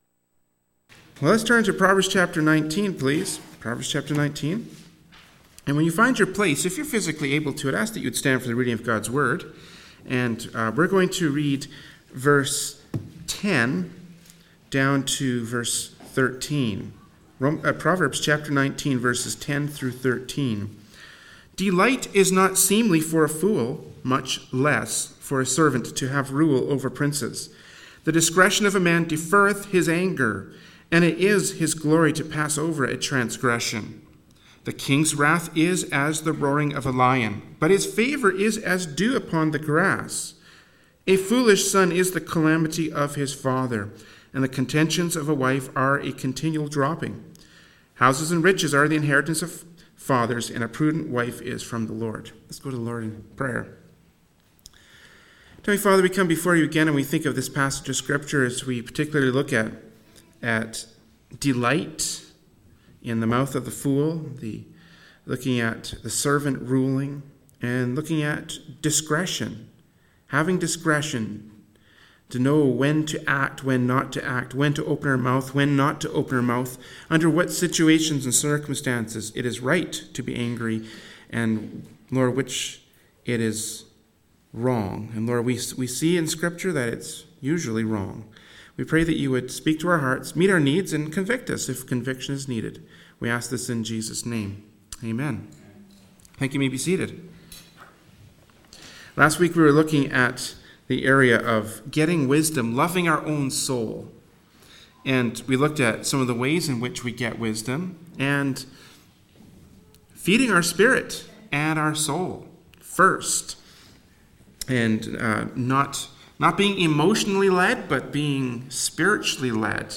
Service Type: Adult Sunday School